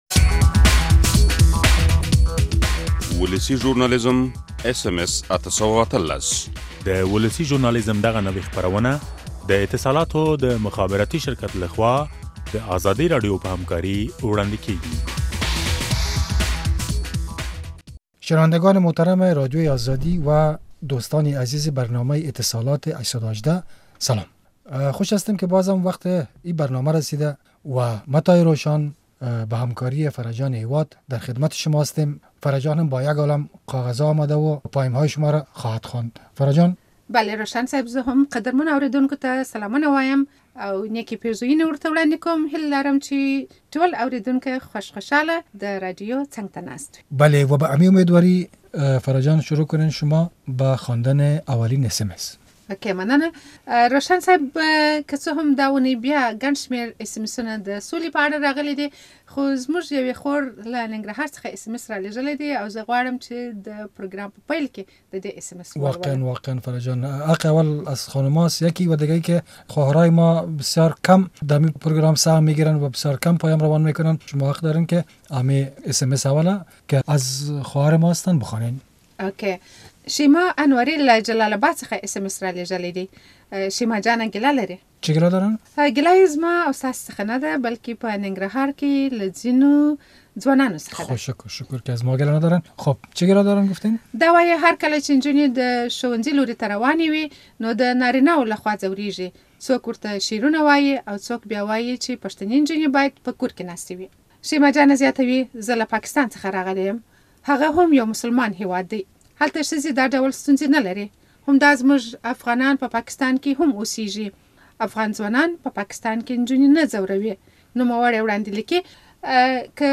اورېدونکو پر خپلو بېلابېلو ستونزو او ځينو روانو چارو خپل پيغامونه رالېږلي، چې تاسې يې په همدې پروګرام کې اورېدلاى شئ.